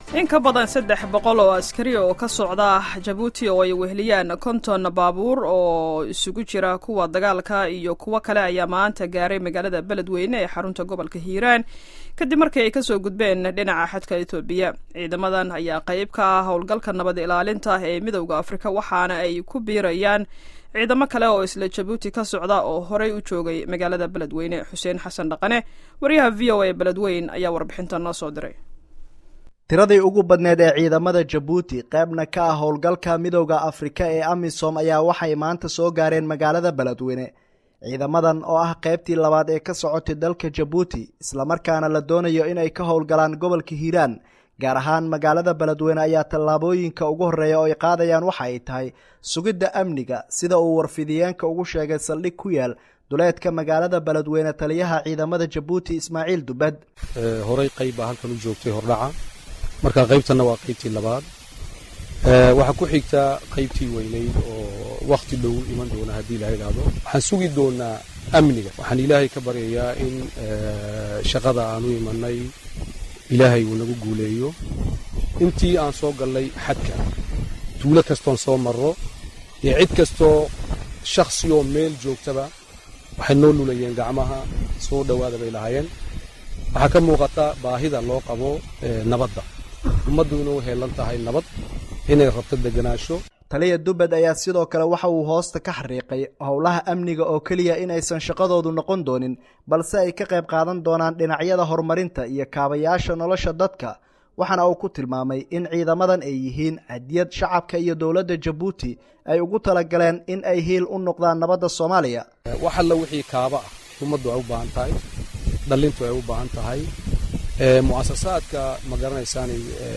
Dhageyso warbixinta imaanshaha ciidanka Djibouti